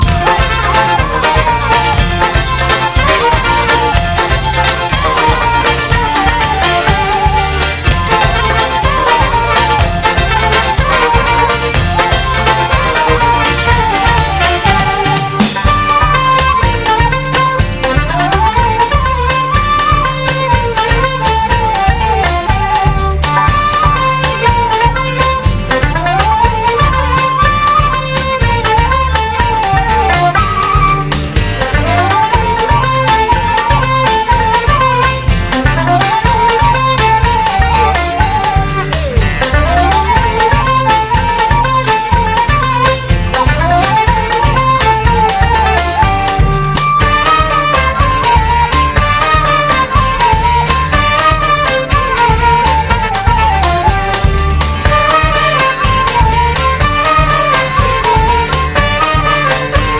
ルンバ・フラメンカ×ファンク/クラブ・ミュージック！
ラテン・ファンク、クンビア、ルンバ・フラメンカなどテンコ盛り！